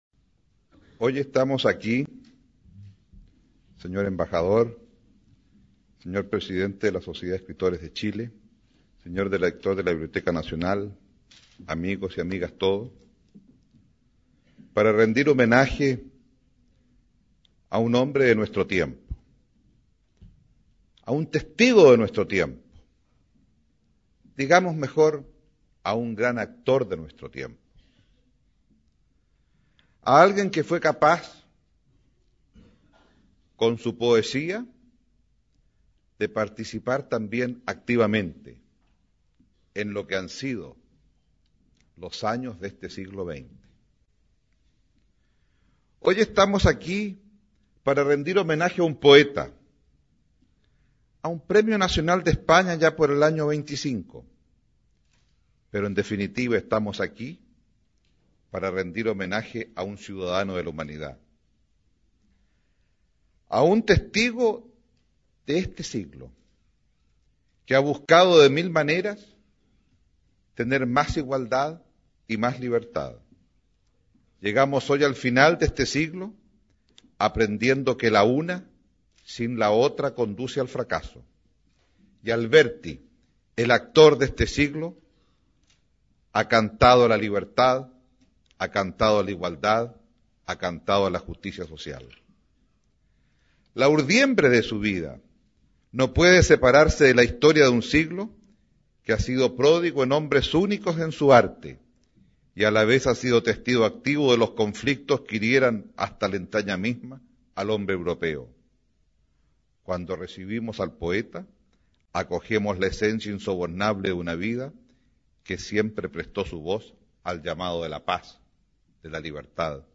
Aquí podrás escuchar el discurso del Ministro de Educación Ricardo Lagos en homenaje al poeta español Rafael Alberti al serle otorgada la Condecoración Gabriela Mistral. El acto se realizó en 1991 en la Biblioteca Nacional de Chile, año en que el ilustre representante de la Generación del 27 visitó nuestro país. Al final del discurso oirás también al propio autor andaluz agradeciendo las palabras y despidiéndose.